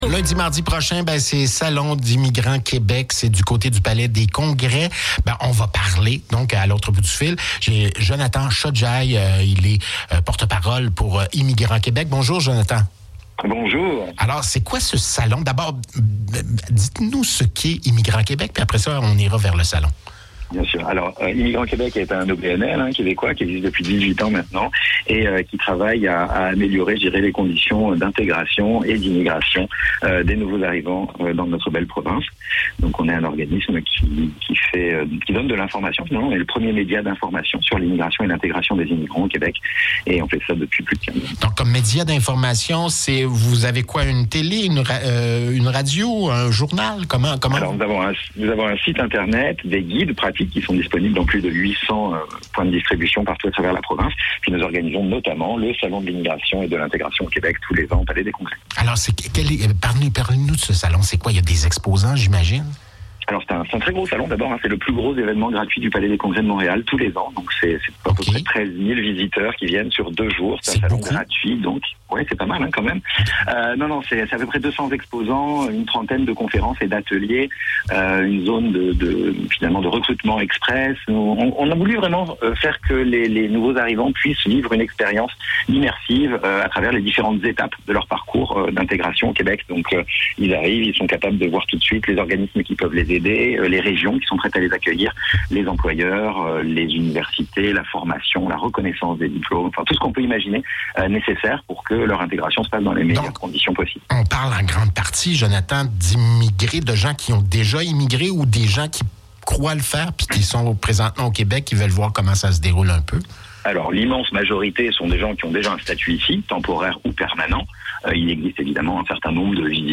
Les entrevues du FM 103,3